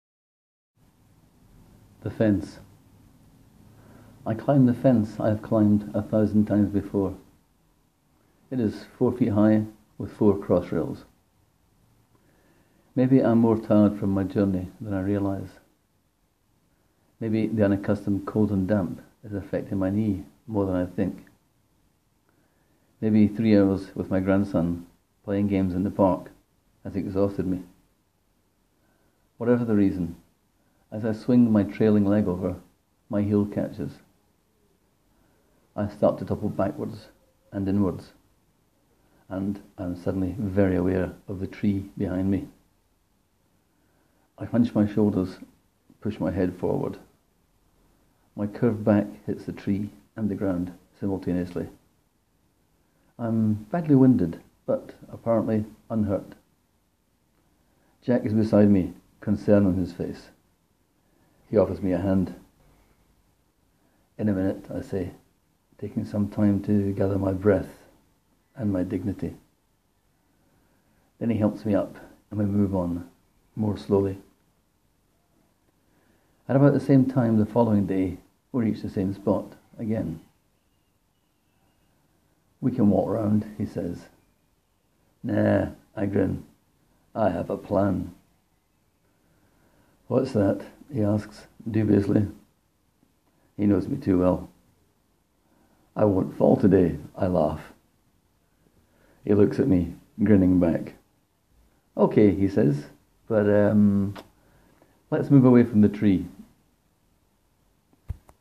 Click here to hear me read the story: